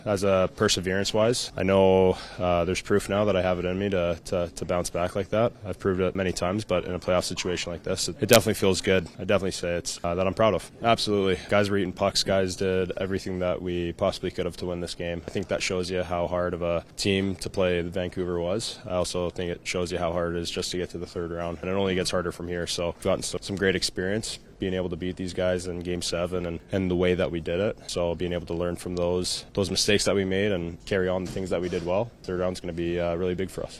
Skinner spoke to the media postgame about his personal journey throughout the playoffs this year.